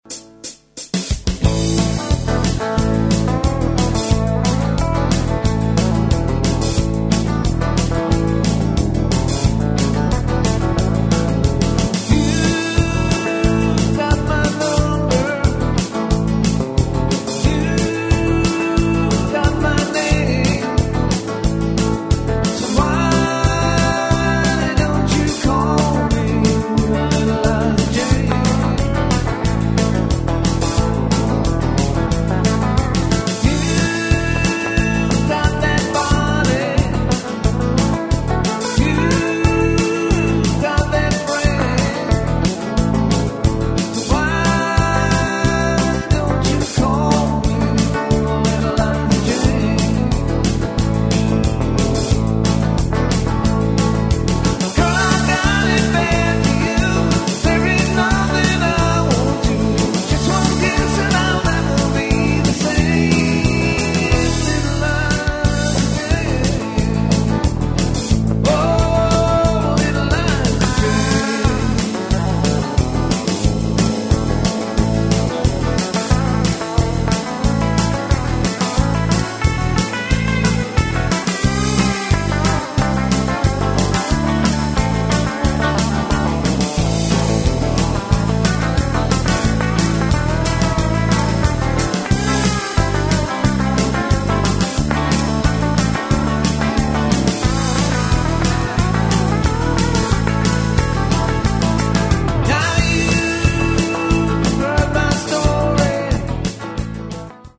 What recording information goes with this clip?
Important note: All the songs below were recorded by the band with no additional musicians. What your hear is what we sound like on stage!